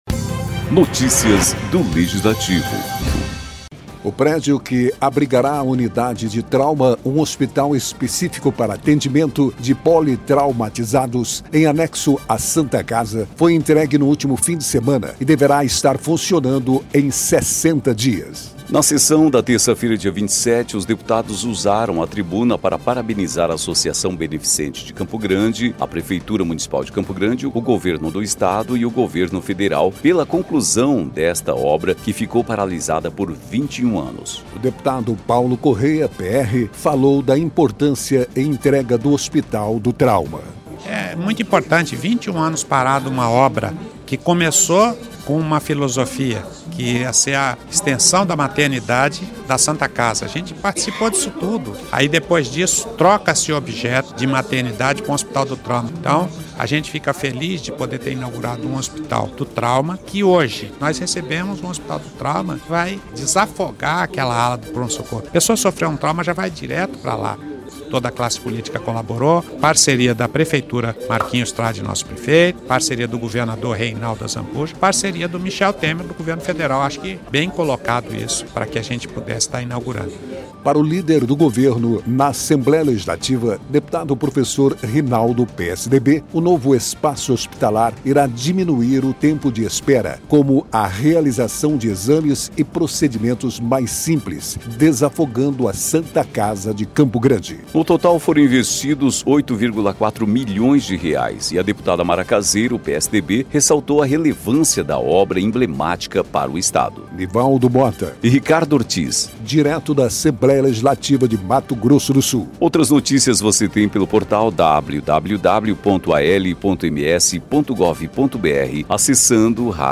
O prédio que abrigará a Unidade de Trauma, um hospital específico para atendimento de politraumatizados, em anexo à Santa Casa, foi entregue no último fim de semana e deverá estar funcionando em 60 dias. Na sessão desta terça-feira (27/3), os deputados usaram a tribuna para parabenizar a Associação Beneficente de Campo Grande (ABCG), a Prefeitura Municipal de Campo Grande, o Governo do Estado e o Governo Federal pela conclusão desta obra que ficou paralisada por 21 anos.